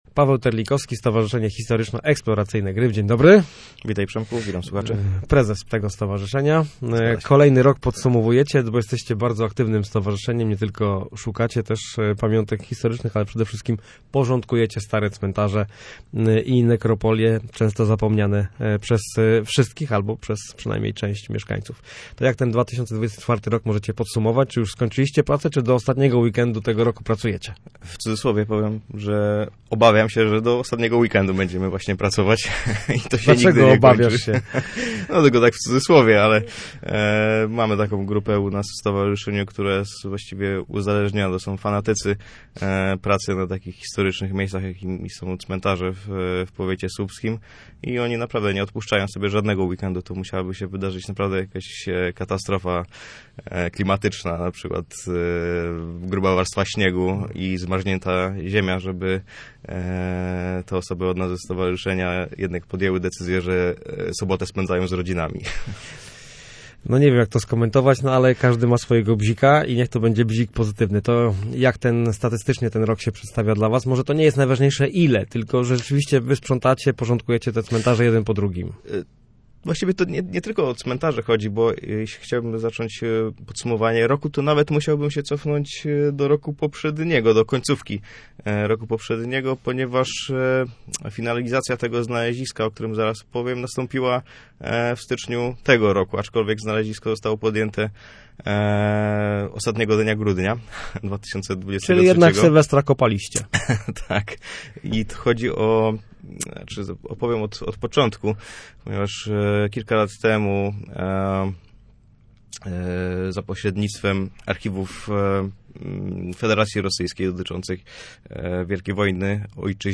Posłuchaj rozmowy naszego reportera